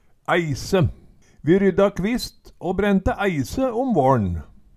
DIALEKTORD